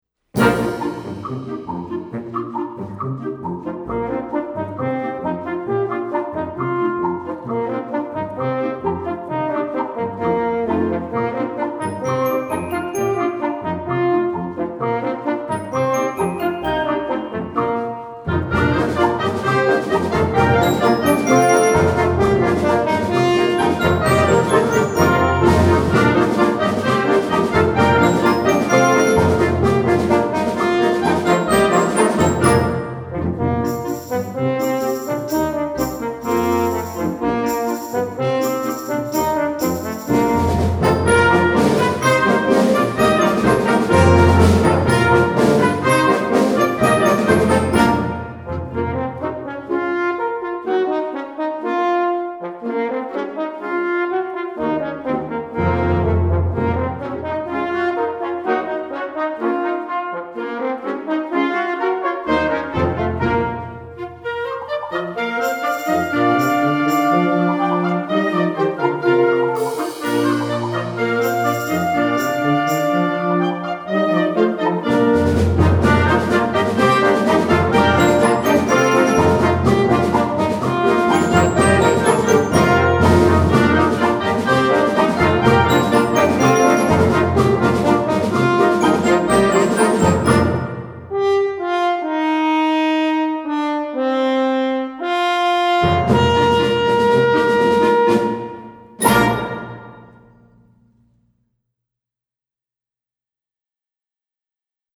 Gattung: Suite für Horn und Blasorchester
Besetzung: Blasorchester